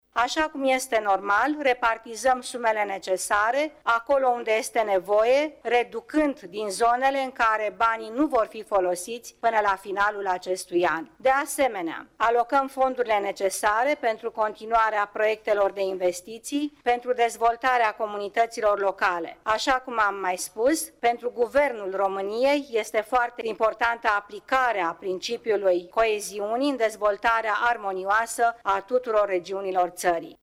Viorica Dăncilă a mai spus că rectificarea bugetară va asigura continuarea investiţiilor: